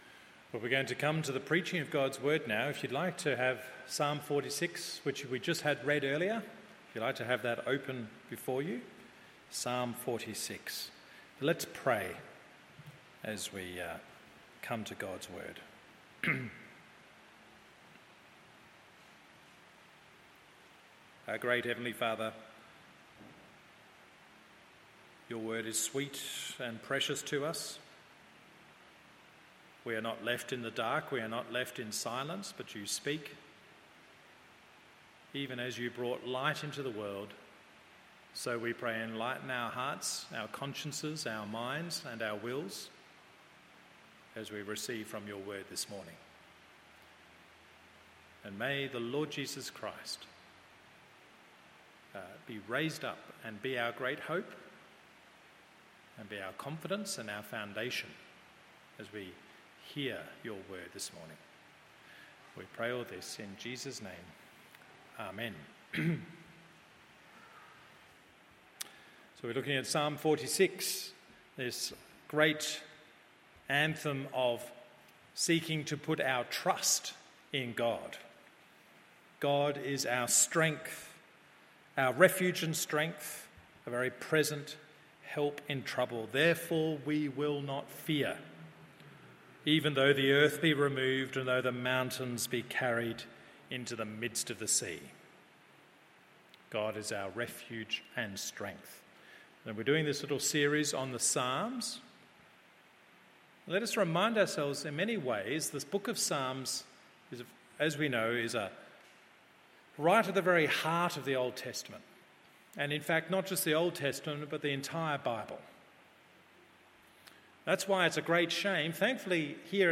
MORNING SERVICE Psalm 46…